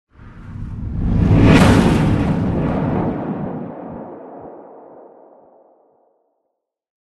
Звуки кометы
Звук удара кометы о поверхность